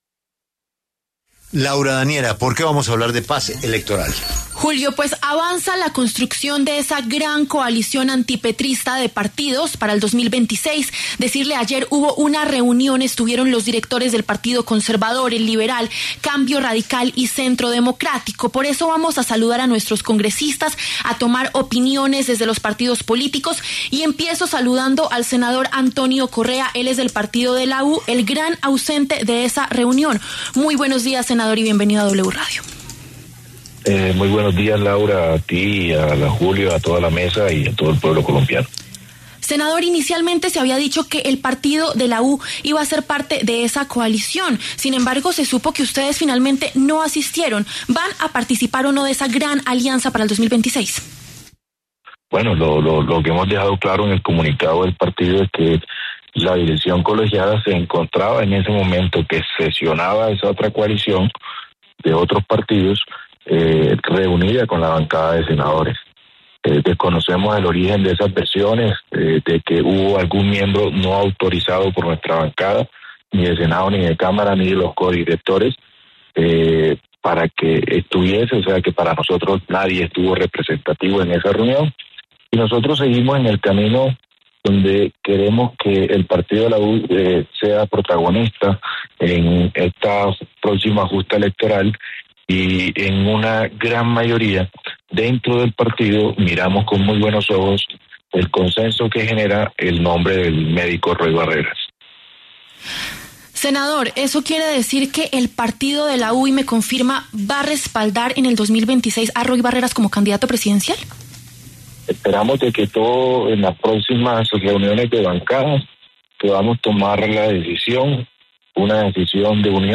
Por los micrófonos de La W pasaron los congresistas Antonio Correa, Mauricio Gómez y Germán Blanco, de los partidos de La U, Liberal y Conservador.